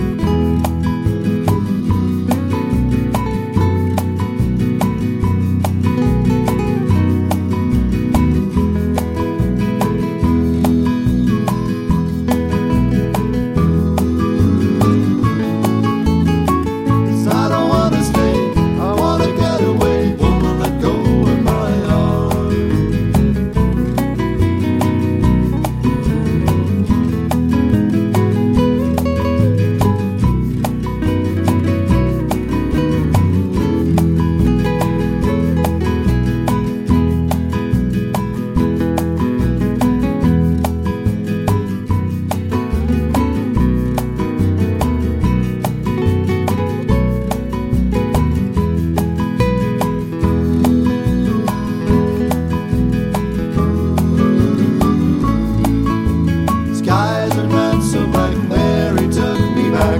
no Backing Vocals Country (Male) 2:55 Buy £1.50